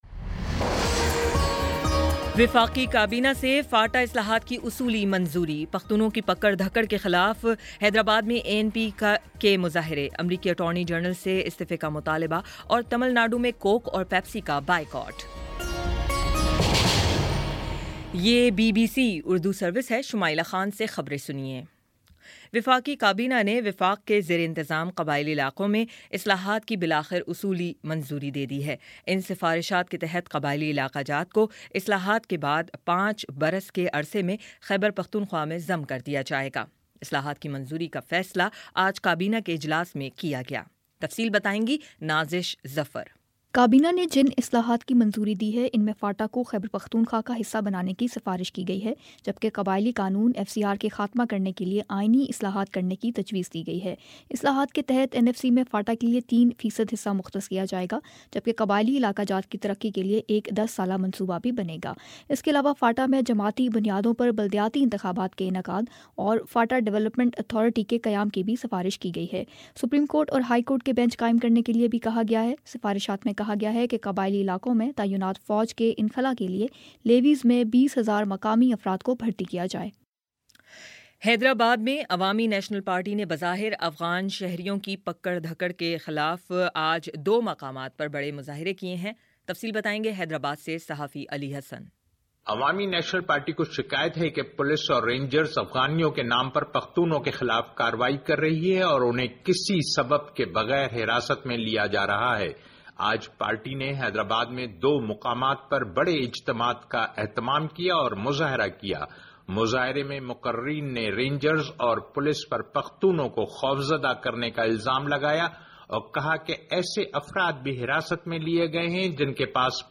مارچ 02 : شام پانچ بجے کا نیوز بُلیٹن